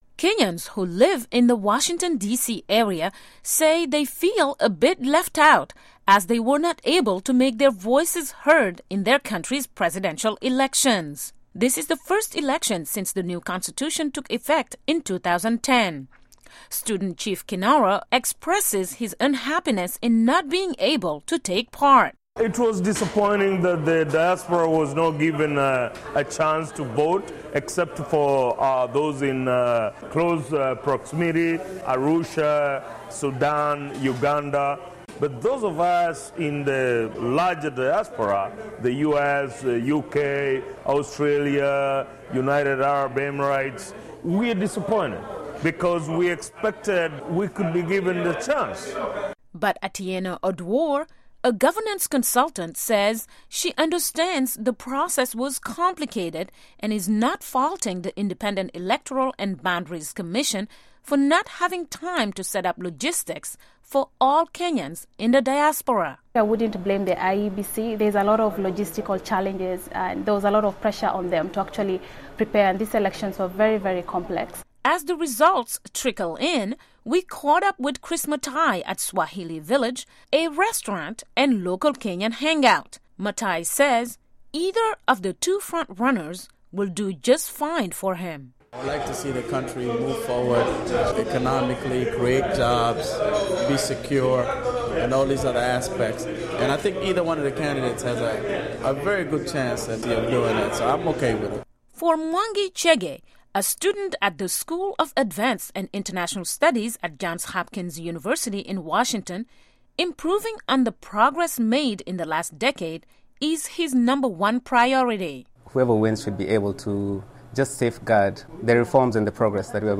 Listen to voices from the Kenyan diaspora on this weeks elections